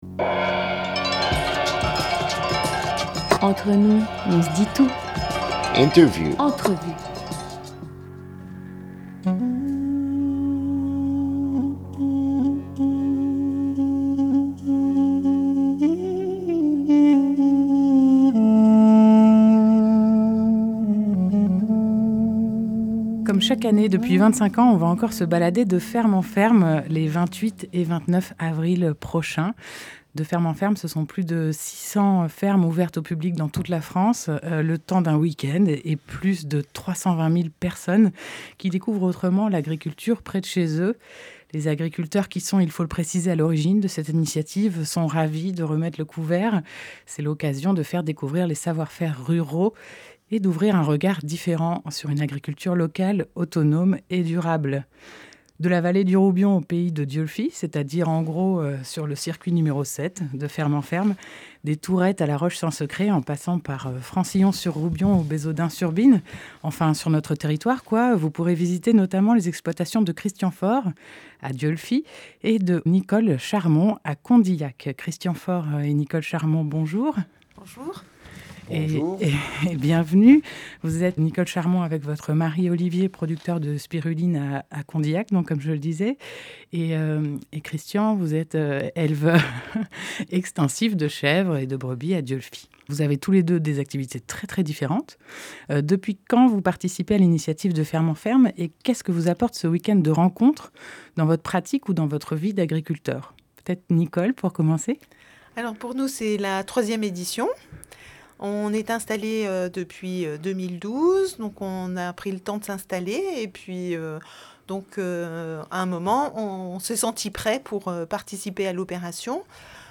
26 avril 2018 17:08 | Interview
ITW-De-ferme-en-ferme.mp3